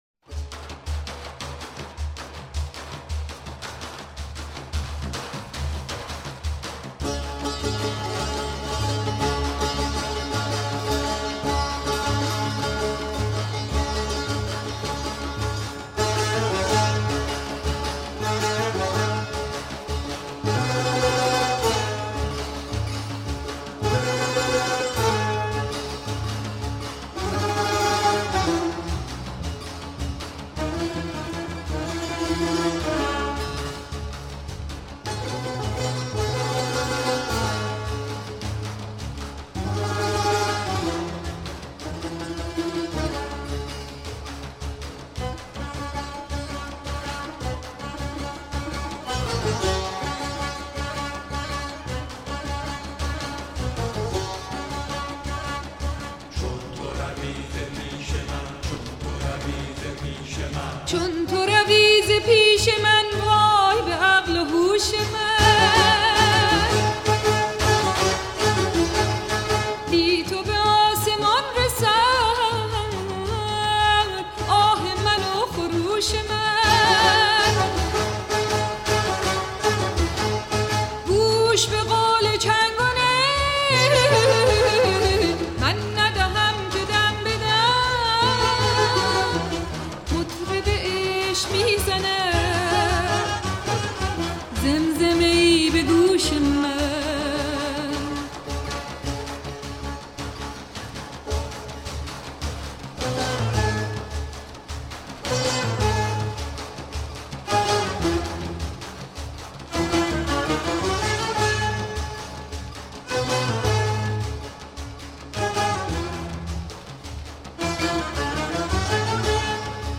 سرود - شماره 2 | تعالیم و عقاید آئین بهائی
مجموعه ای از مناجات ها و اشعار بهائی (سنتّی)